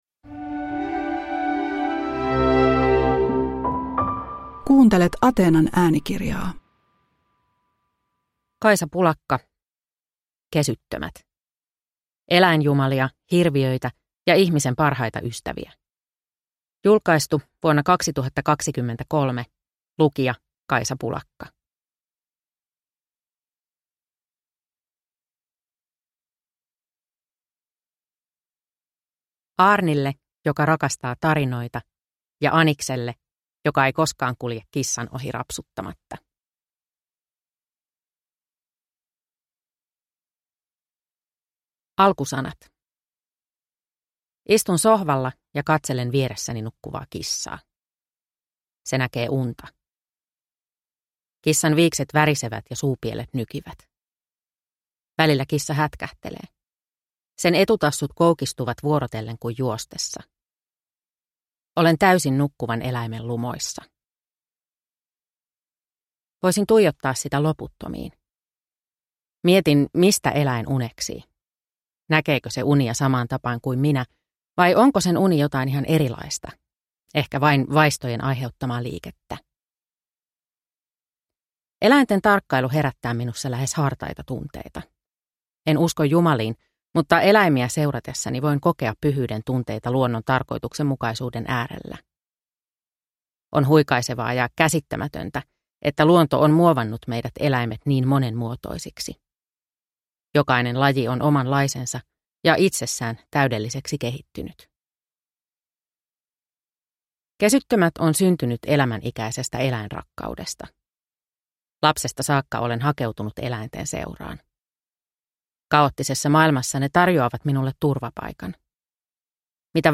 Kesyttömät – Ljudbok